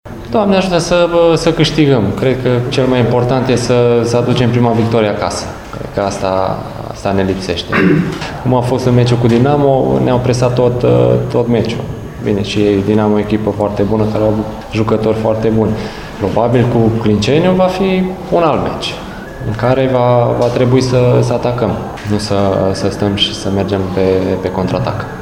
Autorul golului din Ștefan cel Mare, Ioan Hora, afirmă și el că UTA va fi echipa nevoită să construiască în meciul de luni, ora 21,00: